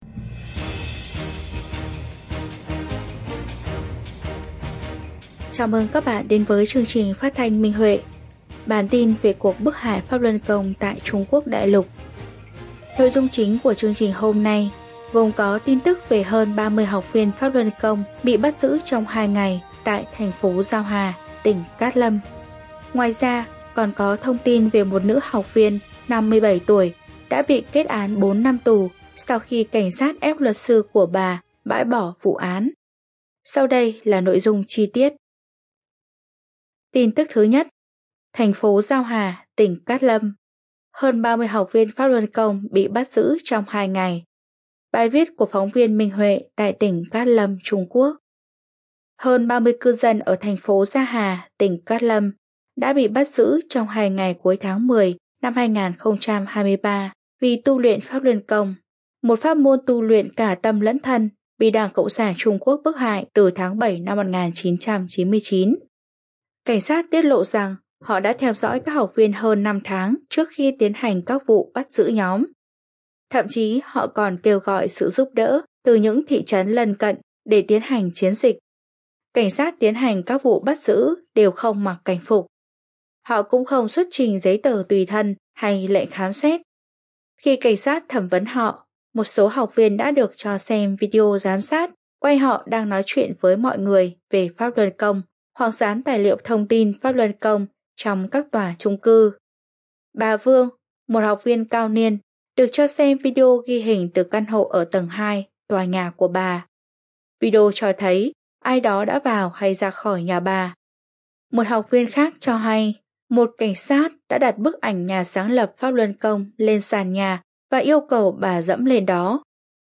Chương trình phát thanh số 50: Tin tức Pháp Luân Đại Pháp tại Đại Lục – Ngày 14/11/2023